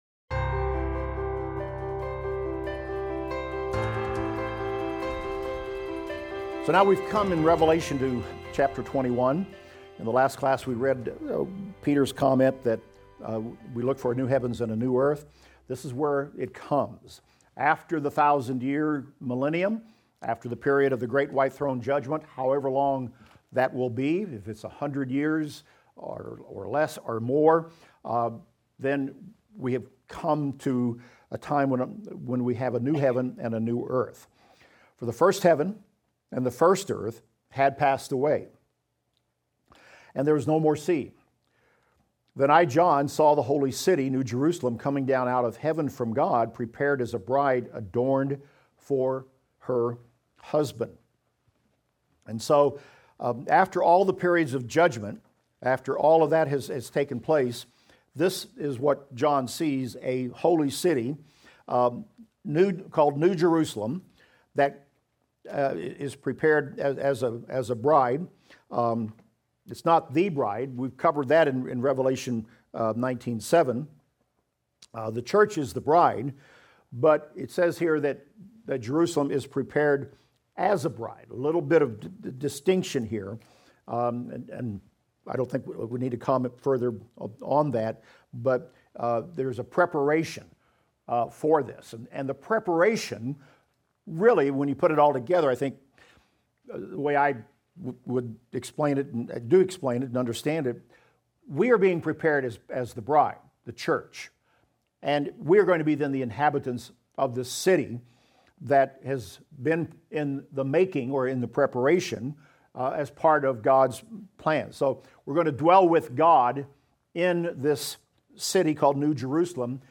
Revelation - Lecture 52 - Audio.mp3